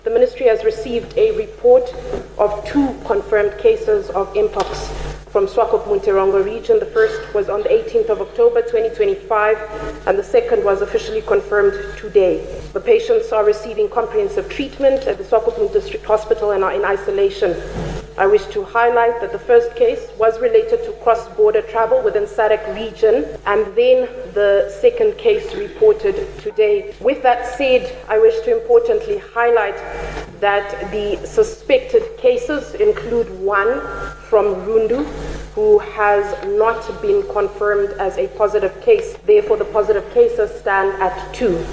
Addressing Parliament this afternoon ahead of the mid-term budget review, Luvindao said both patients are receiving comprehensive treatment at the Swakopmund District Hospital and remain in isolation under close medical supervision.